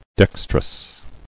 [dex·trous]